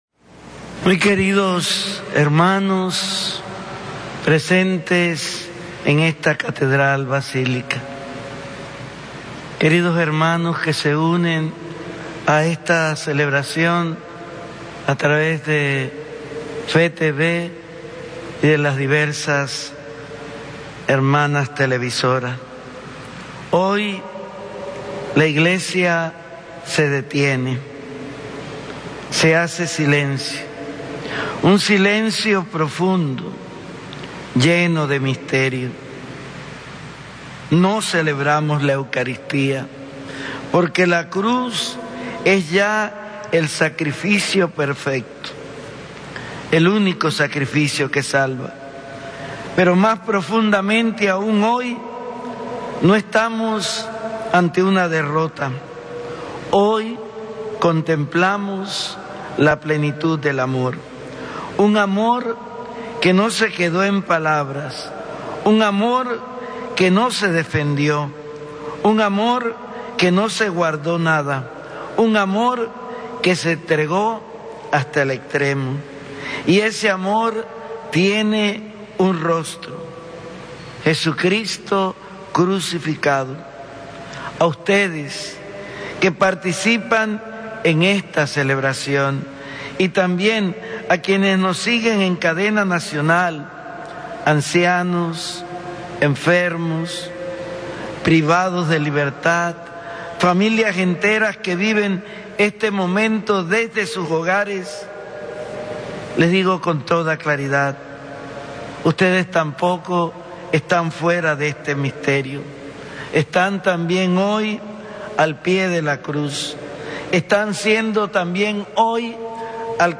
HOMILÍA PARA EL VIERNES SANTO Mons. José Domingo Ulloa Mendieta, O.S.A., Arzobispo de Panamá Arquidiócesis de Panamá – Primada de Tierra Firme Catedral Basílica Santa María la Antigua, viernes 3 de abril de 2026